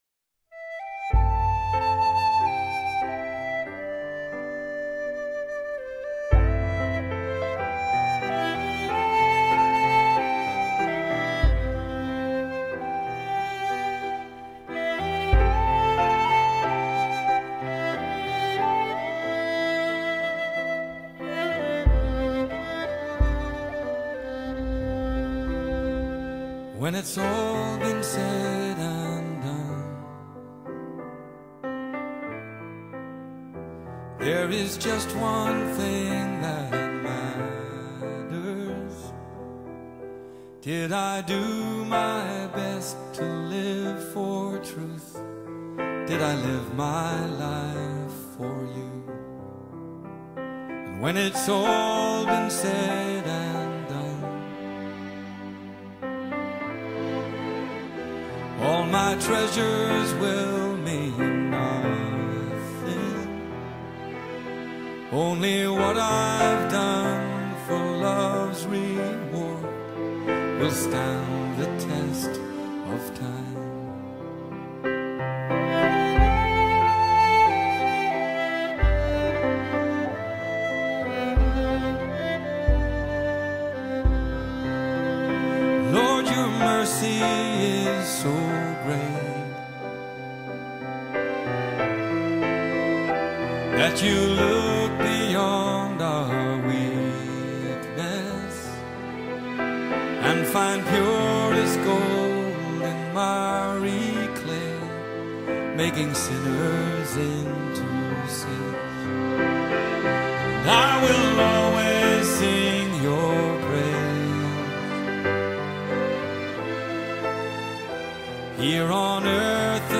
Welcome to this time of worship.
Our service begins with the singing one of two hymns, the more contemporary song being found here and the traditional hymn being here .